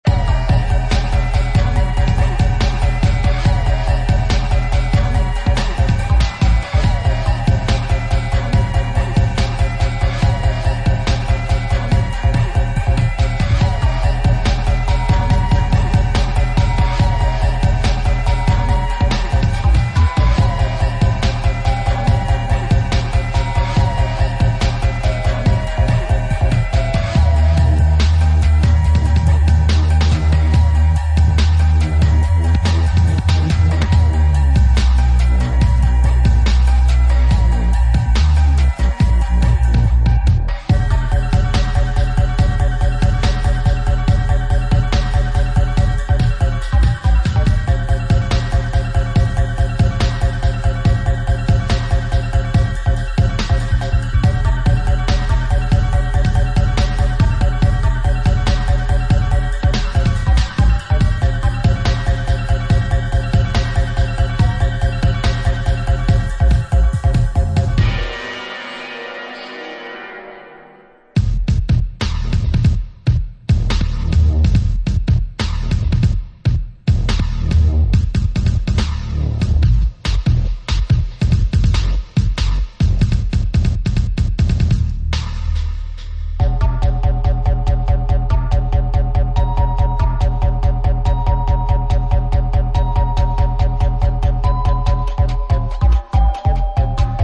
Dope & dramatic grime/dubstep from the Netherlands.